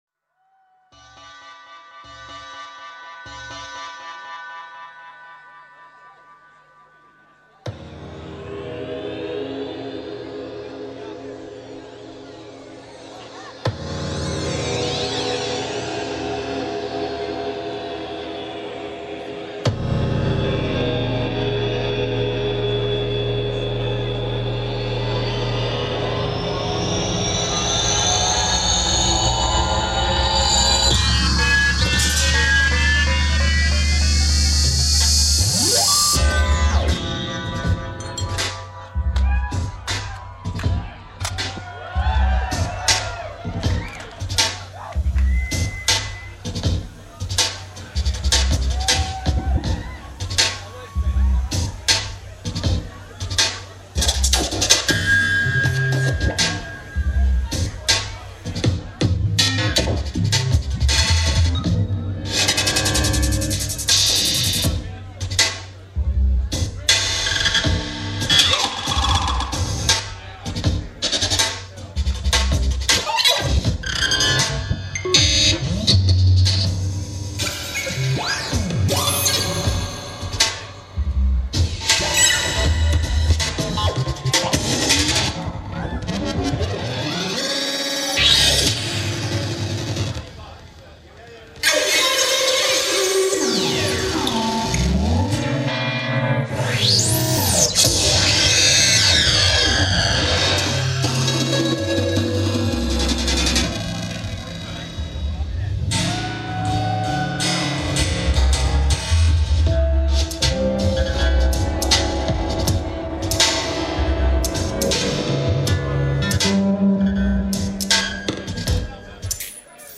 venue Belongil Fields (Byron Bay)